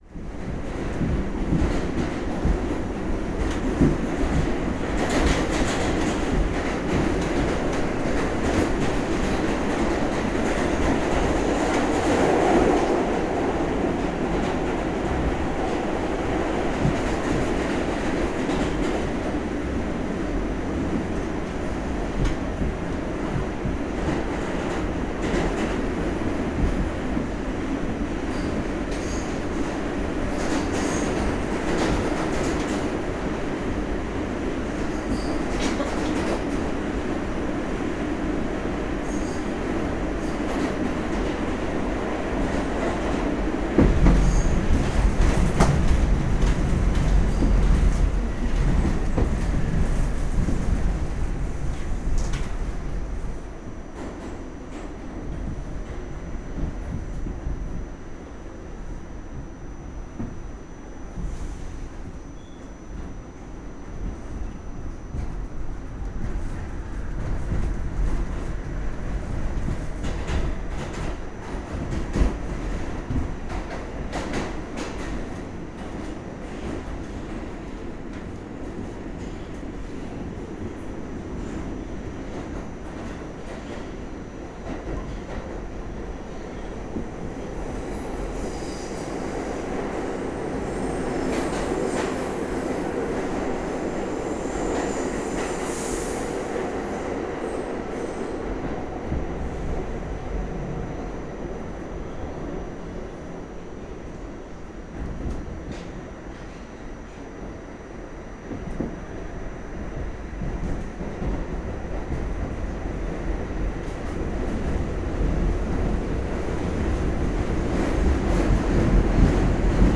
A Train Uptown Bound Afternoon 125 to 168 Sts
A-train interior mass-transit MTA New-York NYC SubwayMTA subway-platform sound effect free sound royalty free Nature